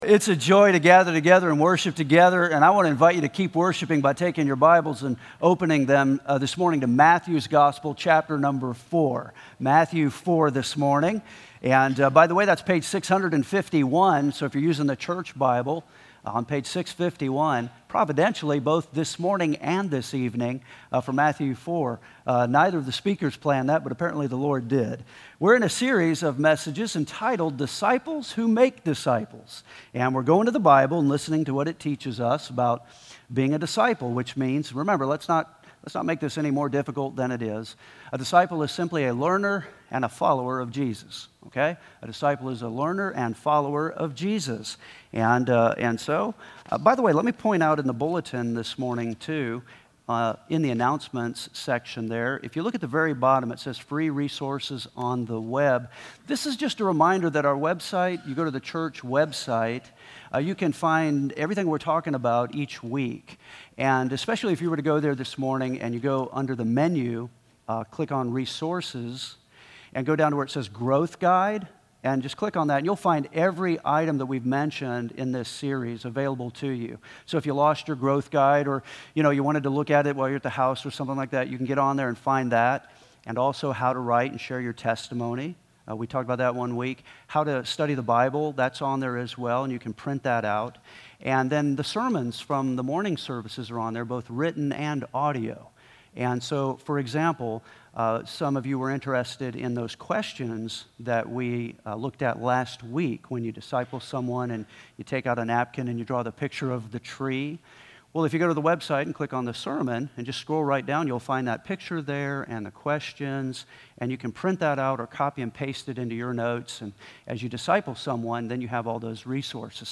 Take your Bibles and join me this morning in Matthew, Chapter 4 (p 651; YV).